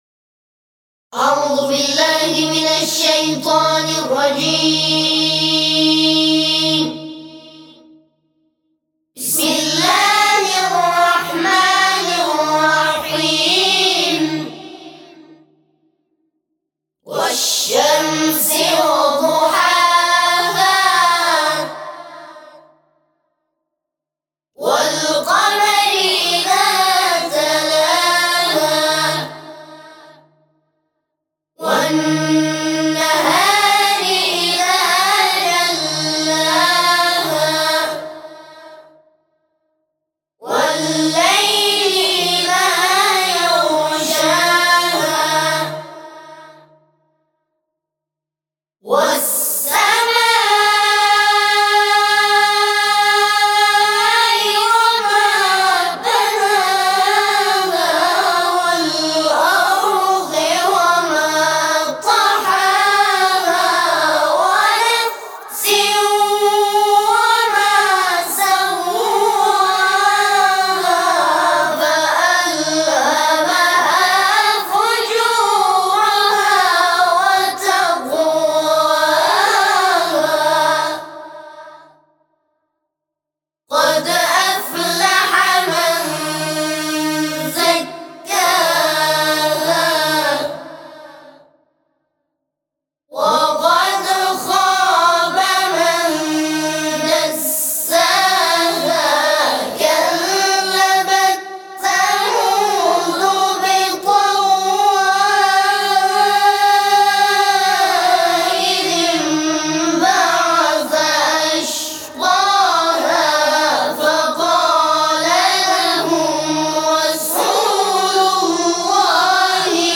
همخوانی سوره شمس | گروه تواشیح بین المللی تسنیم